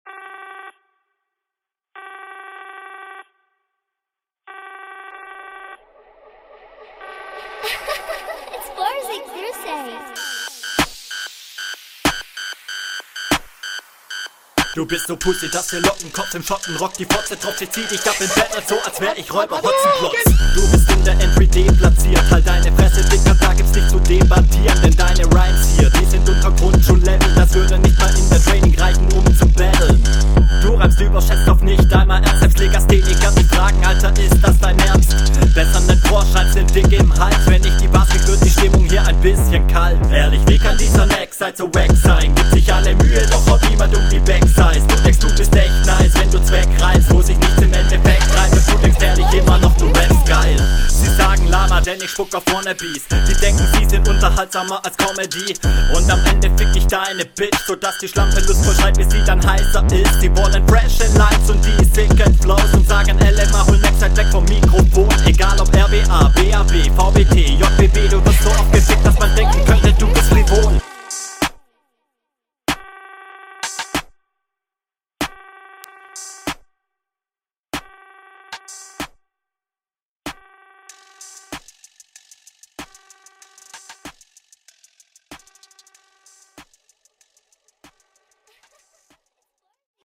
Stimmlich leider nicht so fett wie dein Gegner.